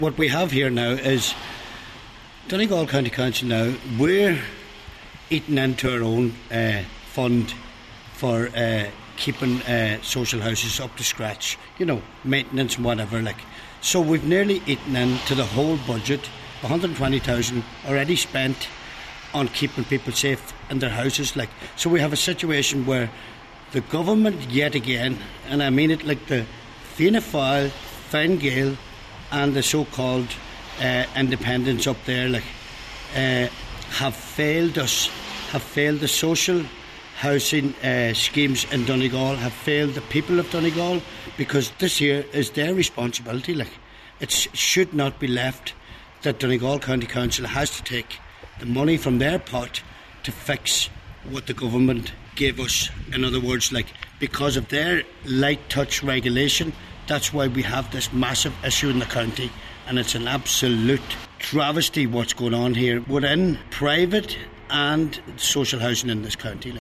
Cllr McGee says the council must write immediately to Minister James Browne to highlight the situation, and remind him of government’s responsibility: